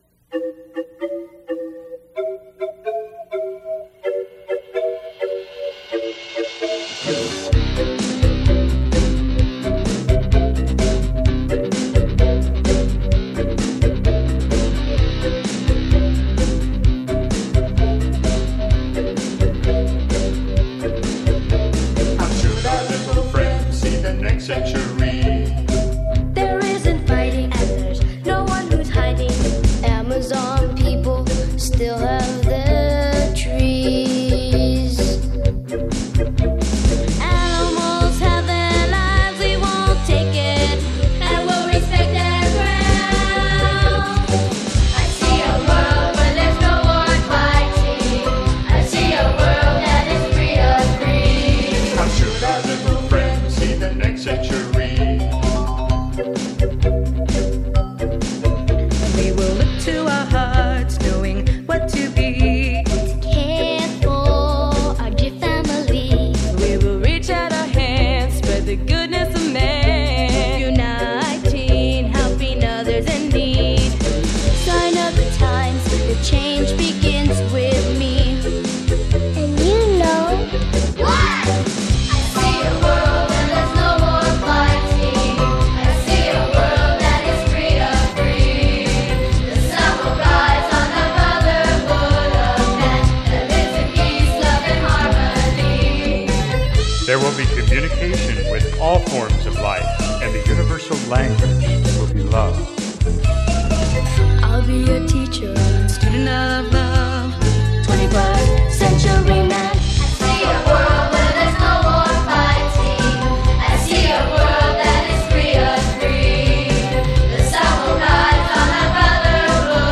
The recording of the song with the children was at Lucas’ Sound Studio in Santa Monica.
21st-Cewntury-Child-KID-SINGERS.mp3